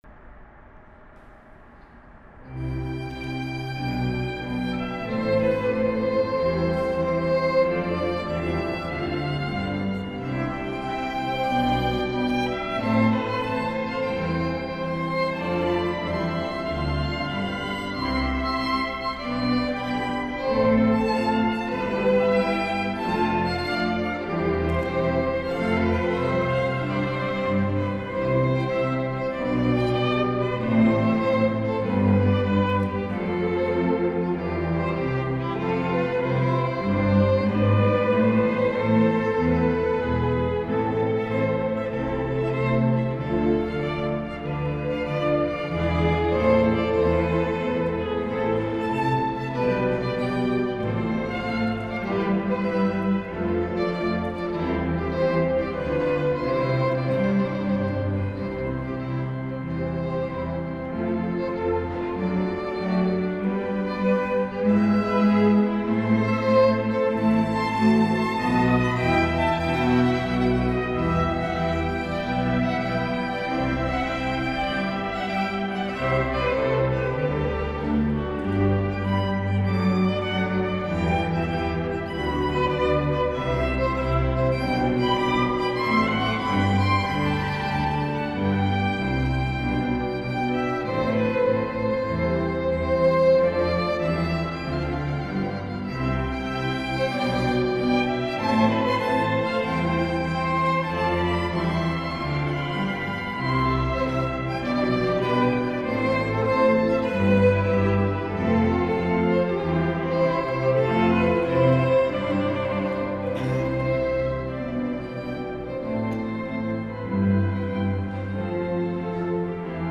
Convento dell’Annunciata
Il  programma "Note di Natale" si è chiuso  con l'Orchestra dell'Assunta di Milano  e la Corale San Gaudenzio di Gambolò che hanno eseguito  il Gloria di Vivaldi e brani di Albinoni e Rodrigo.
in formato audio MP3 di alcuni branii  del concerto